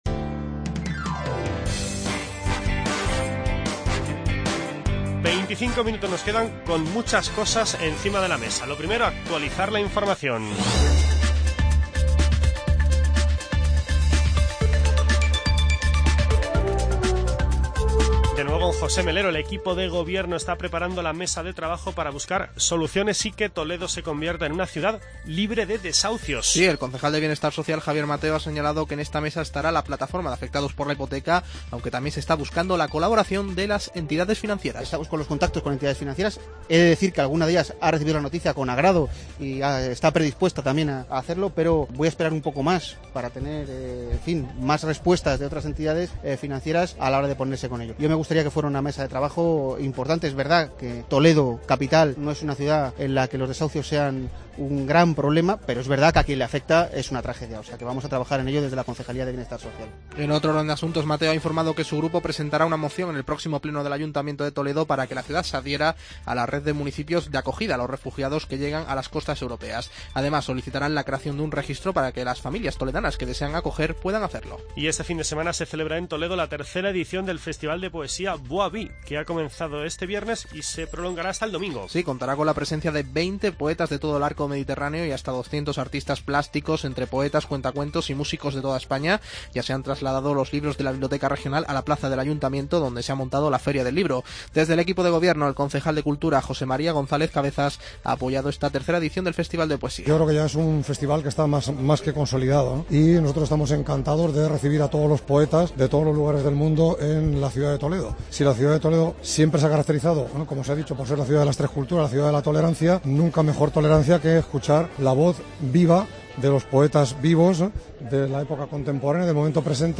En este tramo del programa entrevistamos a Ángel Mariscal, alcalde de Cuenca, y a José Luis Fernández, primer edil de Belvís de la Jara